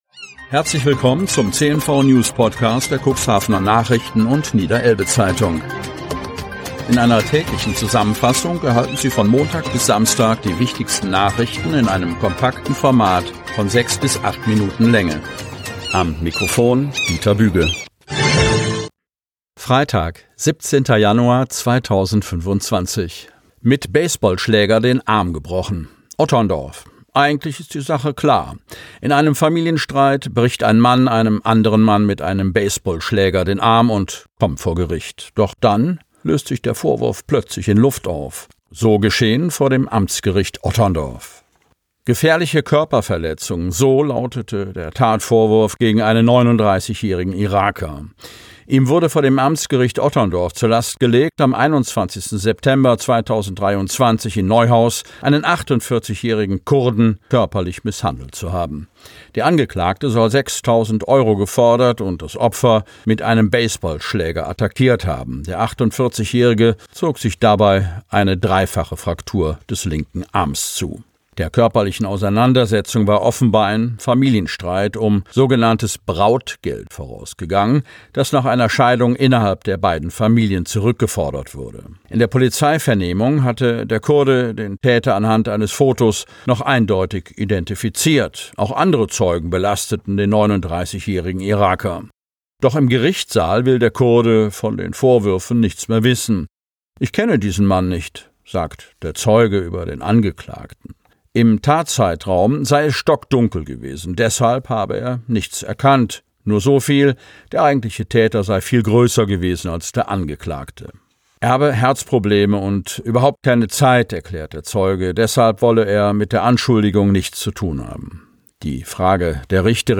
Ausgewählte News der Cuxhavener Nachrichten + Niederelbe-Zeitung am Vorabend zum Hören!